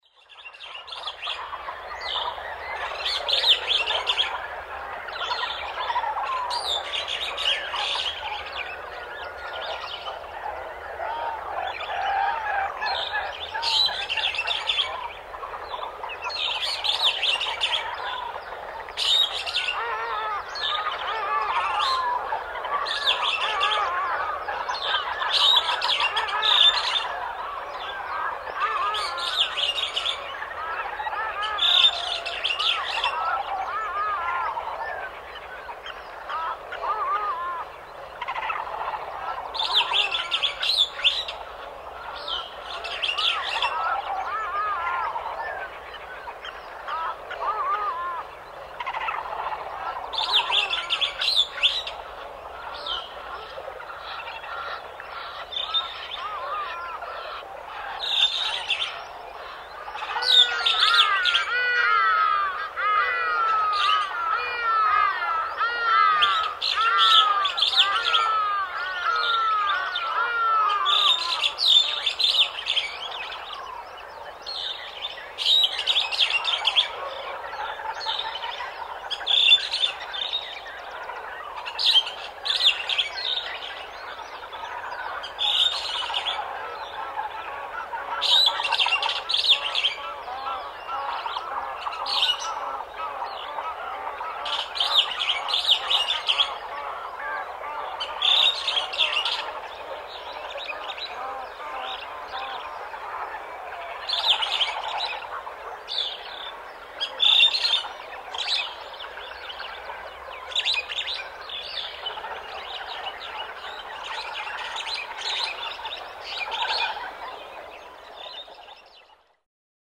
Sonidos-Jungla.mp3
KGgiPJq1sxg_Sonidos-Jungla.mp3